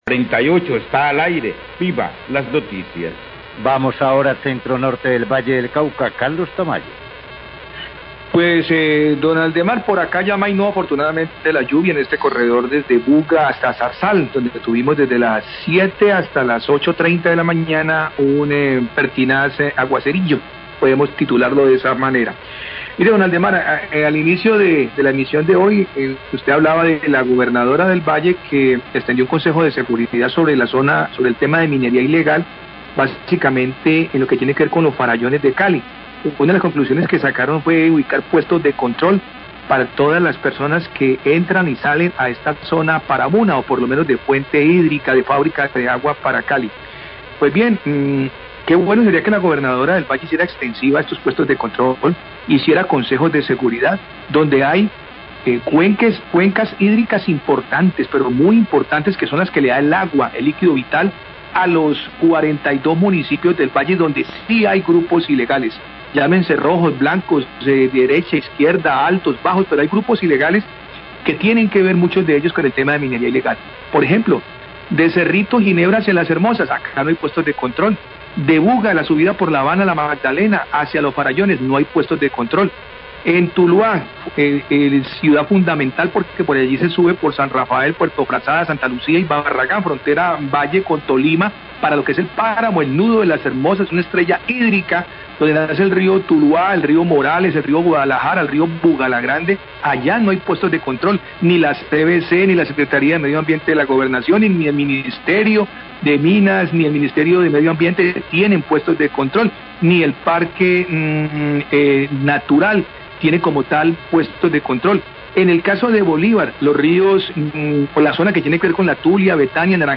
Radio
Corresponsal manifiesta que sería bueno que hubiera puestos de control por parte de la Gobernación, de la CVC, del Minambiente, etc, en otros municipios del departamento donde hay grupos ilegales y se realiza minería ilegal cerca de fuentes hídricas.